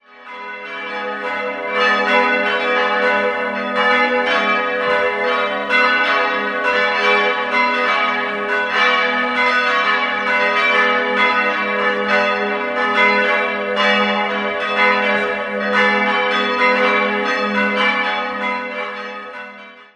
4-stimmiges ausgefülltes Gis-Moll-Geläute: gis'-h'-cis''-dis'' Die Glocken wurden 1950 von der Gießerei Petit&Edelbrock in Gescher gegossen.